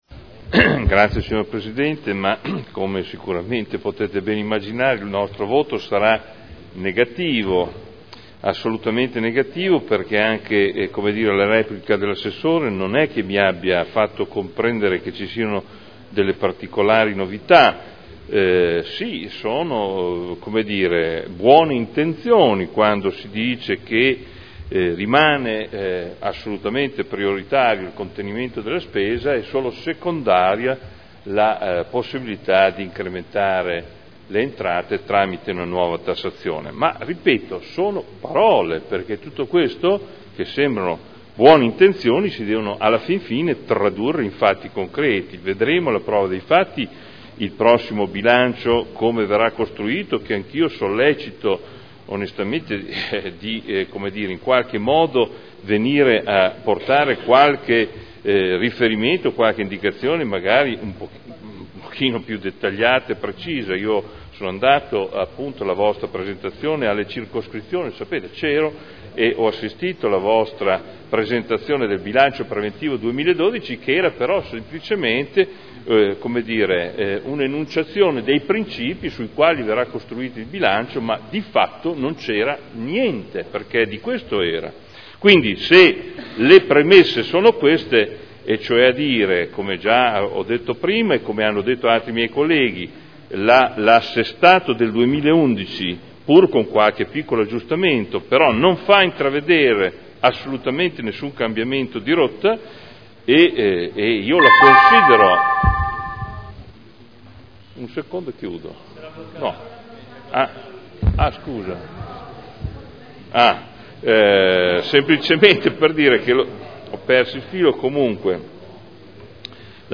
Seduta del 28 novembre Proposta di deliberazione Bilancio di previsione 2011 - Bilancio pluriennale 2011-2013 - Programma triennale dei lavori pubblici 2011-2013 - Assestamento - Variazione di bilancio n. 3 Dichiarazioni di voto